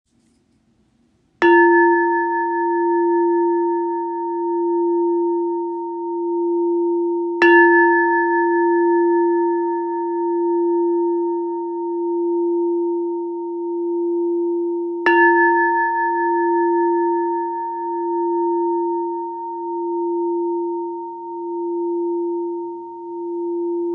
Zpívající křišťálová mísa
Křišťálová mísa se smaragdem Duch VÁŽKY - tón F, 4. čakra srdce
432 Hz
Křišťálová mísa alchymická - tón F